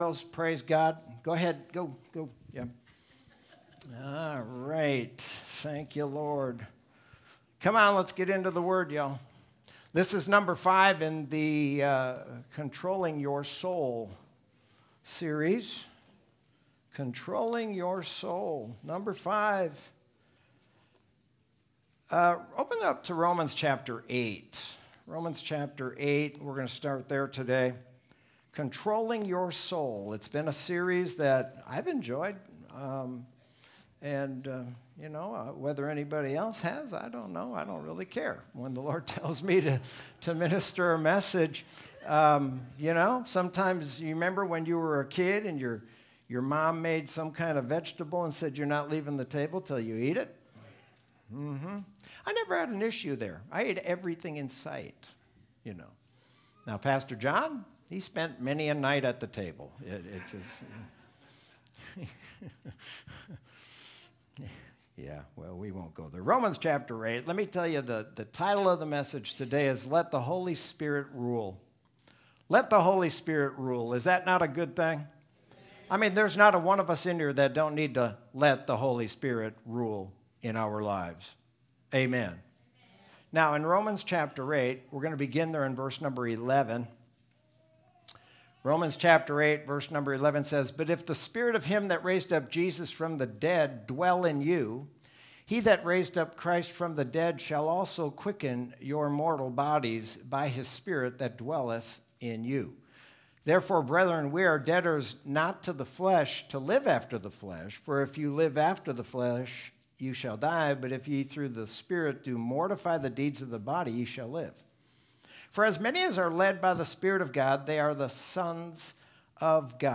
Sermon from Sunday, August 23, 2020.